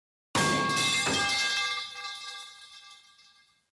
pipefall.mp3